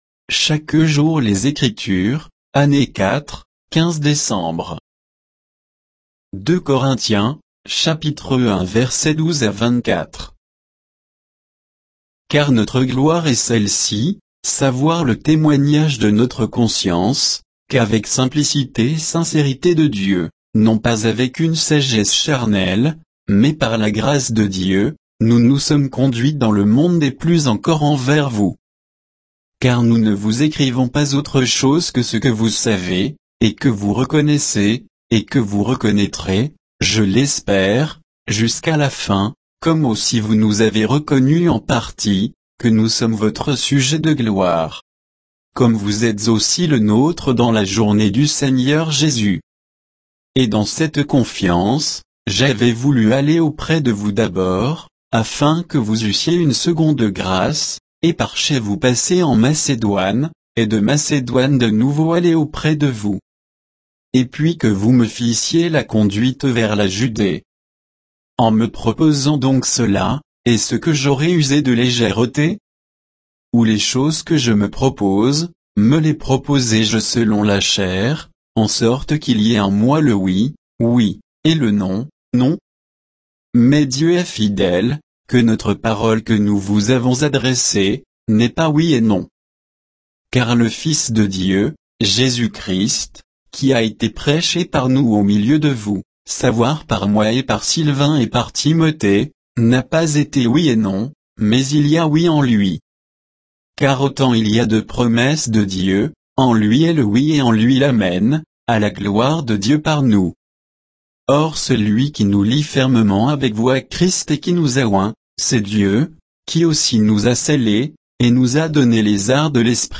Méditation quoditienne de Chaque jour les Écritures sur 2 Corinthiens 1